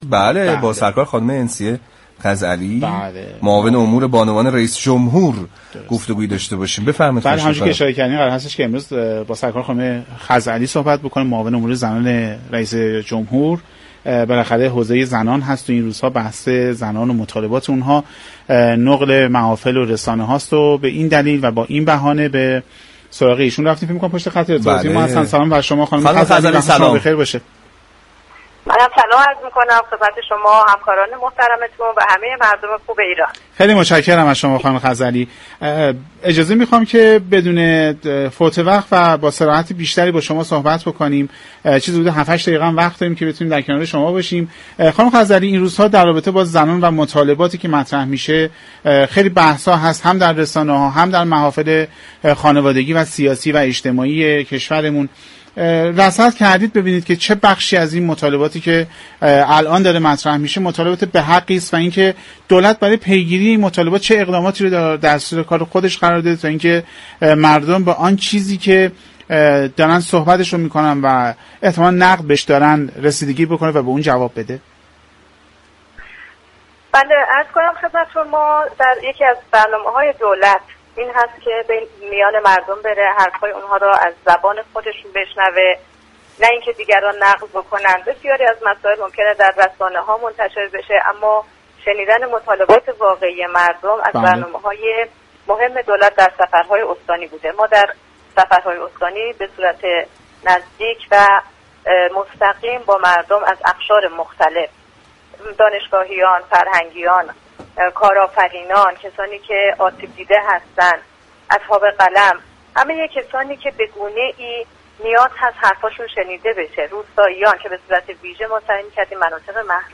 به گزارش پایگاه اطلاع رسانی رادیو تهران؛ انسیه خزعلی معاون امور زنان رئیس جمهور در گفت و گو با برنامه سعادت آباد 18 آبان ماه رادیو تهران با اشاره به مطالبات زنان در جامعه كنونی و اقدامات دولت گفت: یكی از برنامه های دولت سیزدهم این است كه در سفرهای استانی به میان مردم از اقشار مختلف برود و مطالبات را به طور مستقیم از زبان خودشان بشنود.